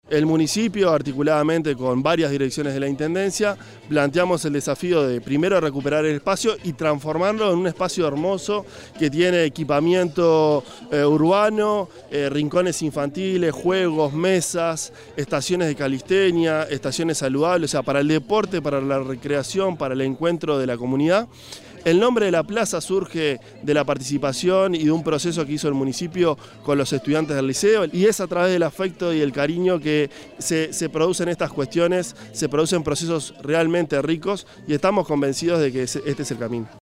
El Director General de la Agencia de Vivienda y Convivencia Ciudadana de la Intendencia de Canelones, Rodrigo Amengual, en la inauguración del nuevo espacio público Unión en Los Cerrillos
audio_rodrigo_amengual_0.mp3